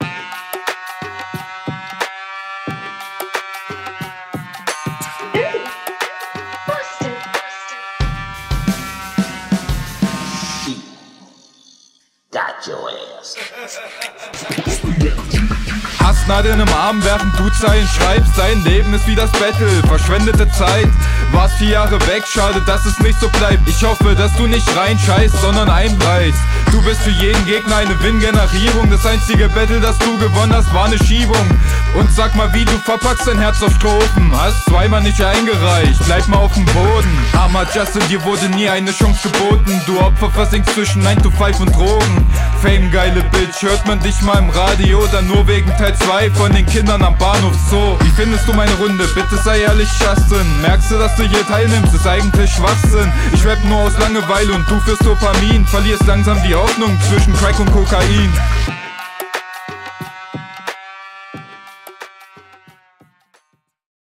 Der Flow klingt noch ziemlich unroutiniert, da sind mehrere Stellen leicht off bzw. gehetzt.
Der Beat geht sehr hart!